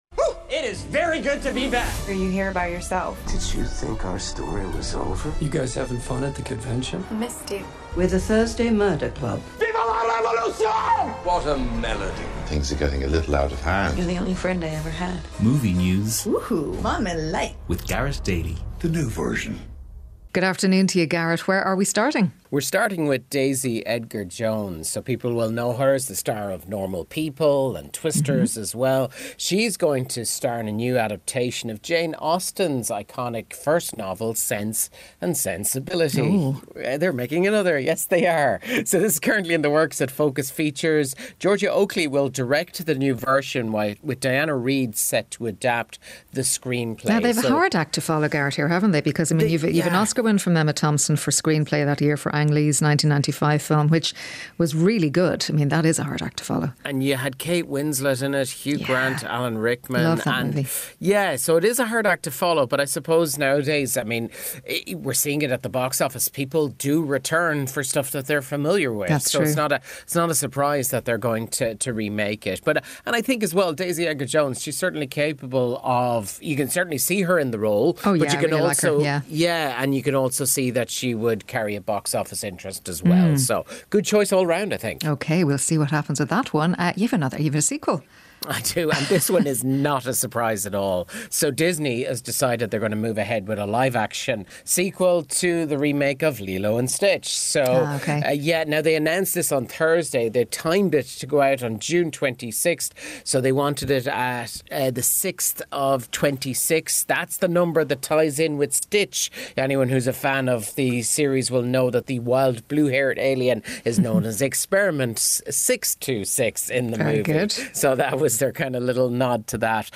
Movie news, trivia and interviews